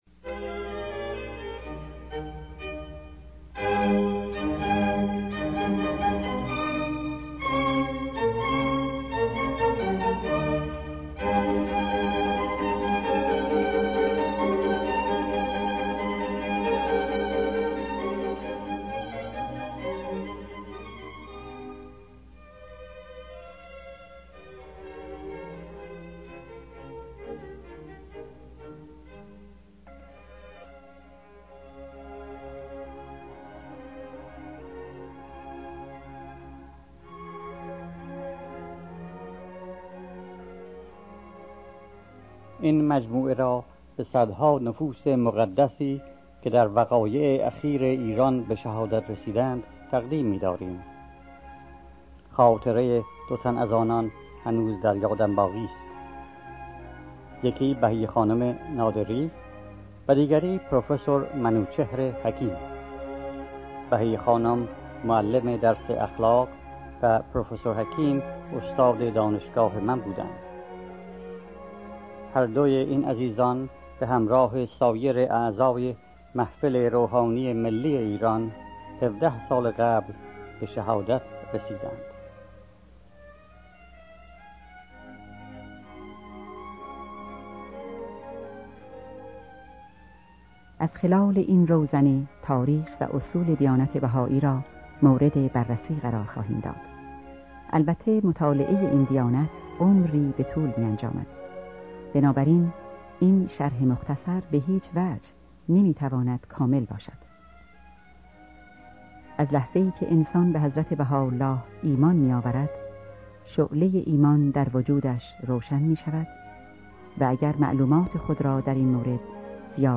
These files are edited from the original cassette tape masters, removing some of the tape filler music, along with the WTTP trailers on may of the tapes. Most of the audio faults (tape print-through, mis-speak repeats, and loud breathing sounds were removed, but the original content was preserved, and folows the transcripts closely.